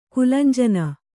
♪ kulanjana